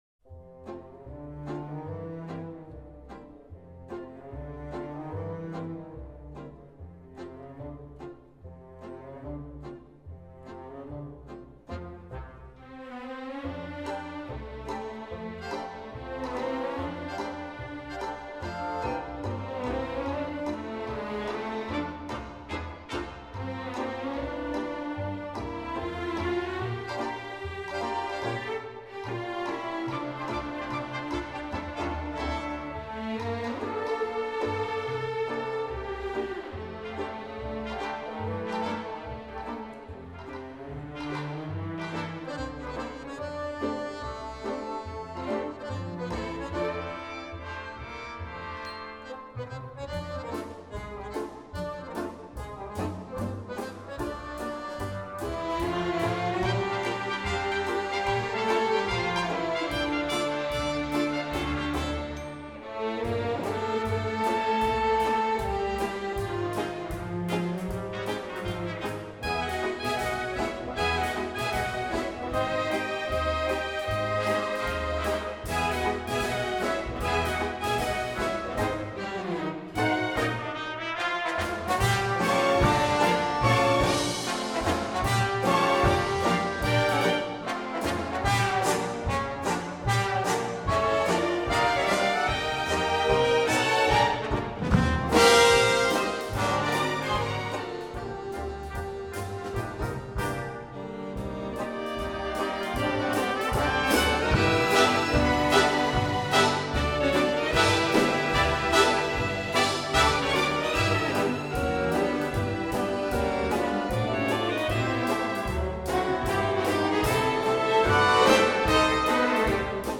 1966   Genre: Musical   Artist